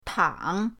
tang3.mp3